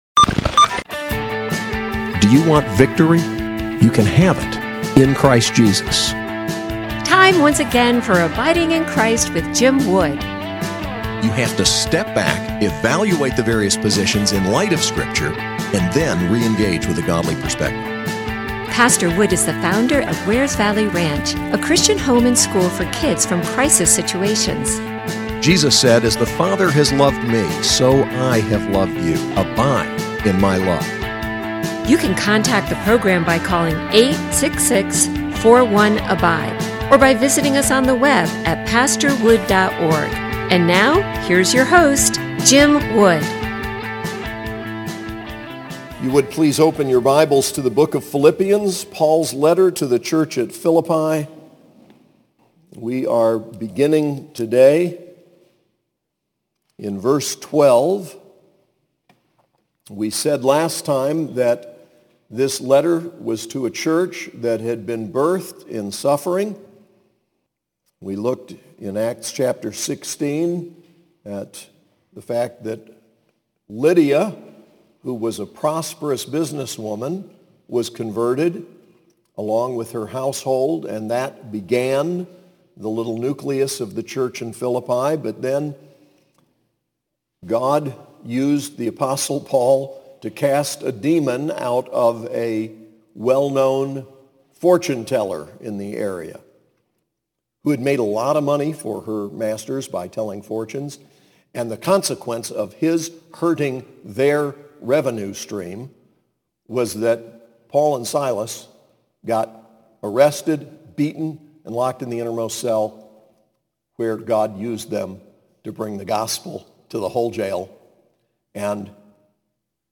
SAS Chapel: Philippians 1:12-30